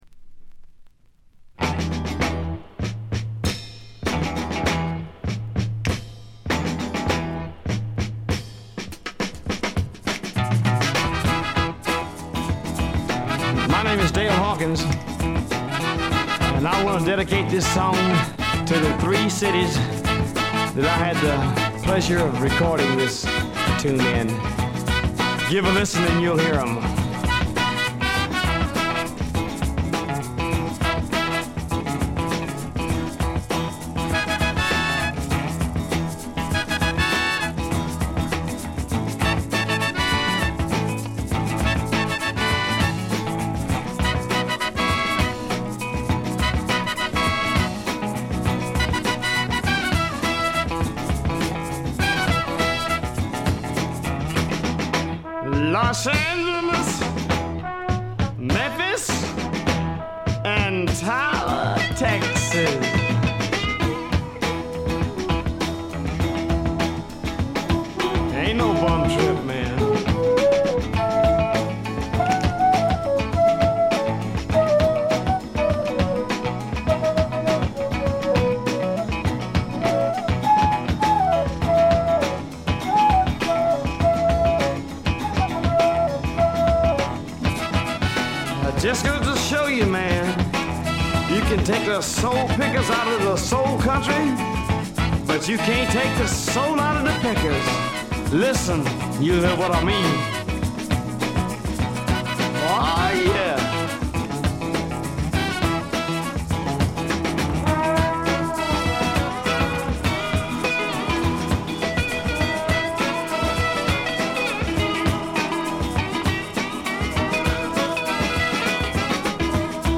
ほとんどノイズ感無し。
文句なしのスワンプ名盤。
試聴曲は現品からの取り込み音源です。